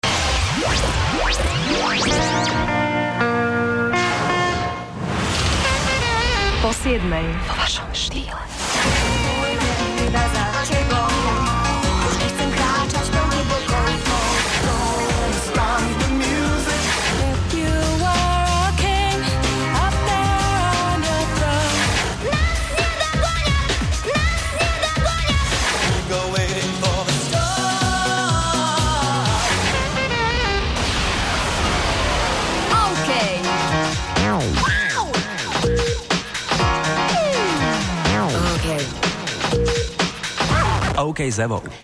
otvoril novú hodinu zostrihom skladieb.